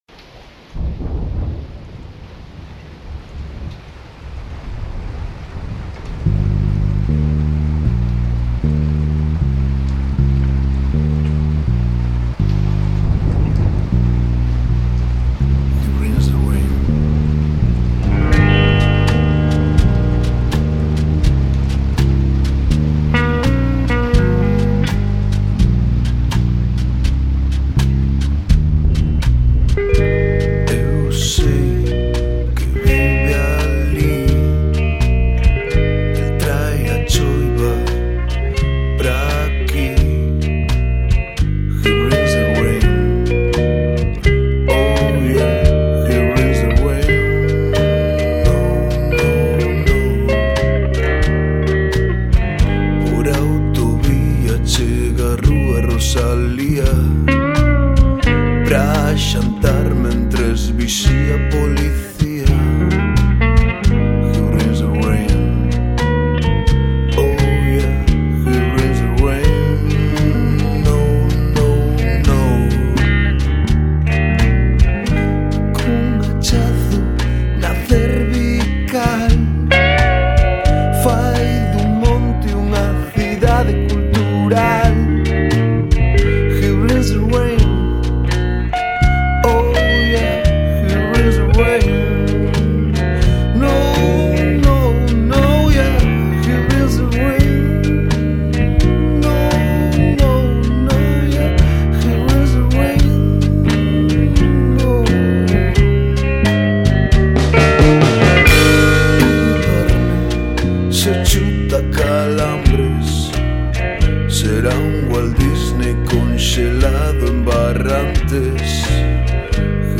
cuarteto